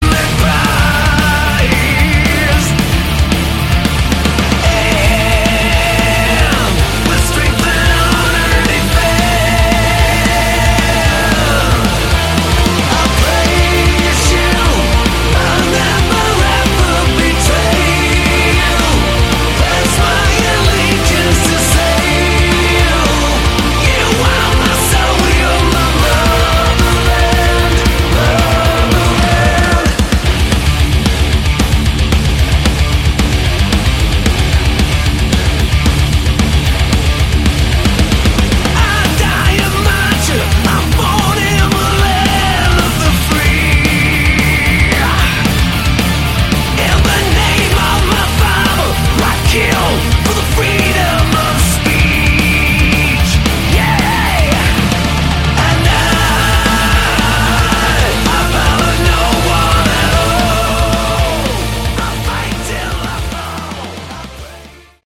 Category: Hard Rock